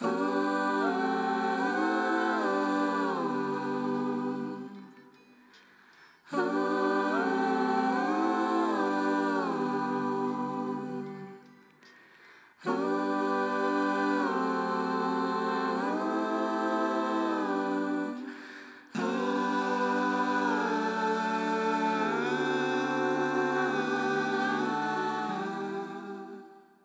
Processing of backing vocals.
Dry: